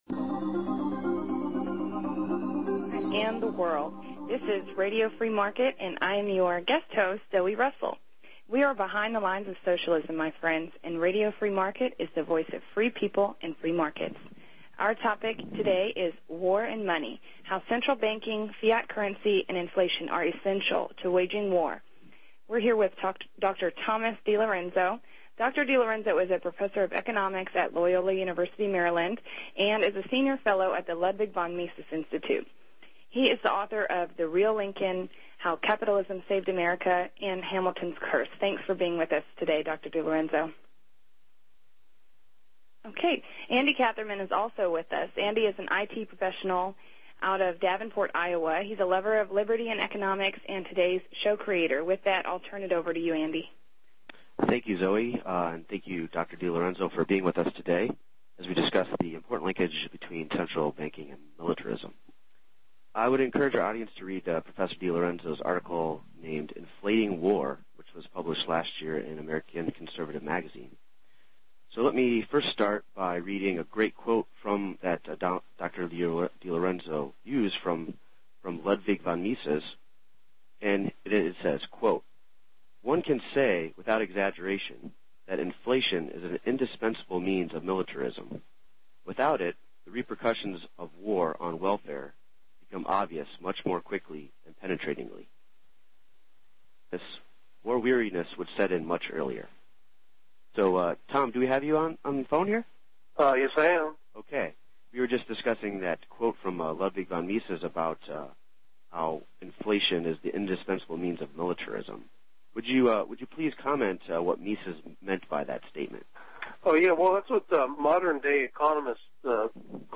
**LIVE CALL-IN SHOW** War and Money